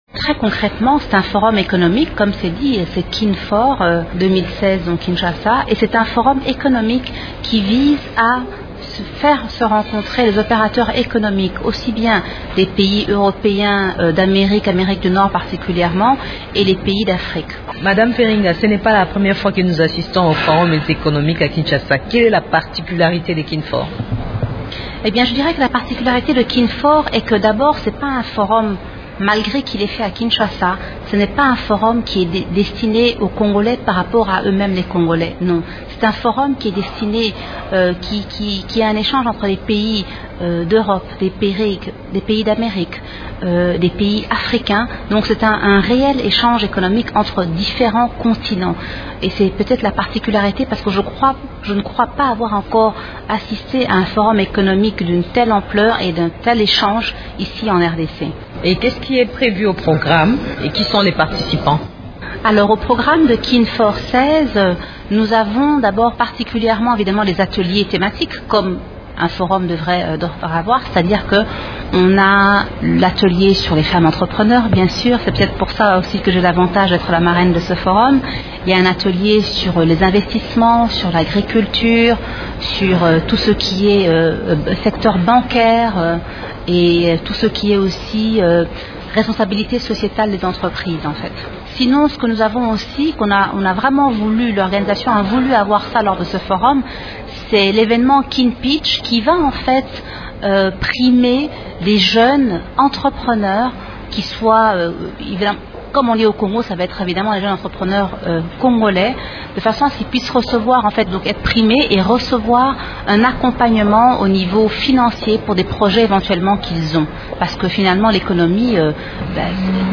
web-entretien-_kinfor.mp3